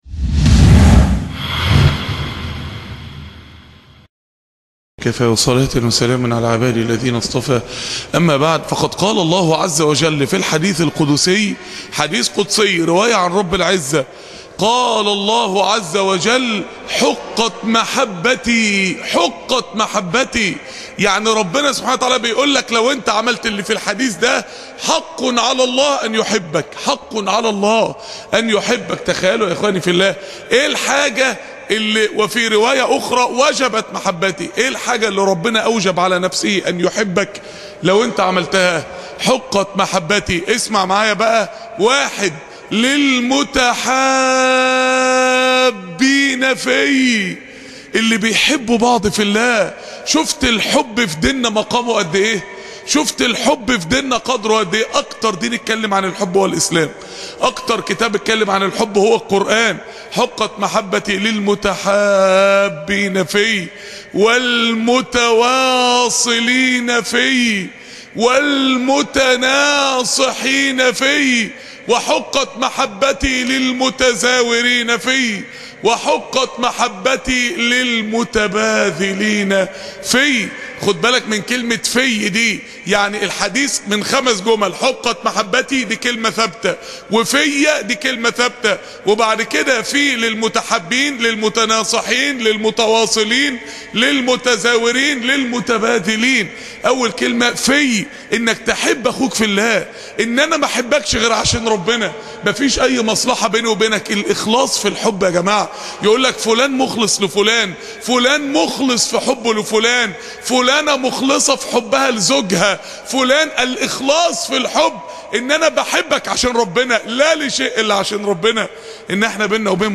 مسجد الصفطاوي بالمنصورة